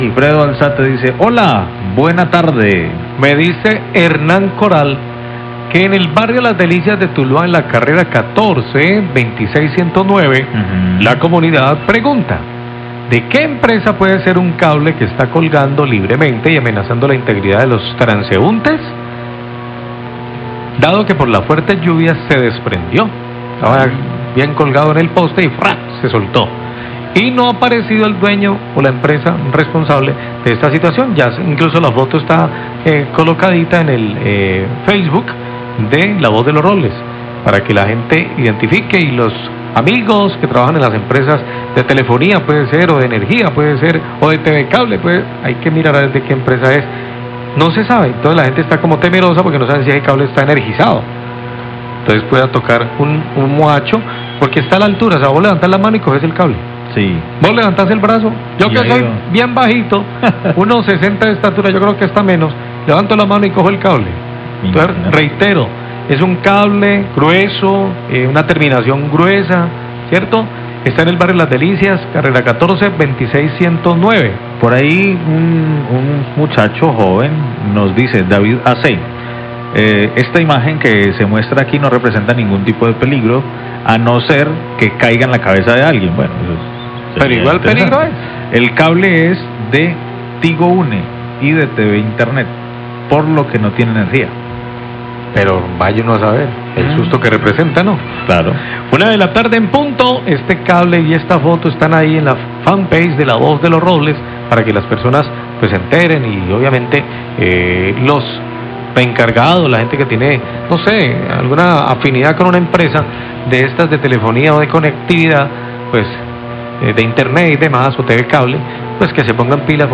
reporte oyente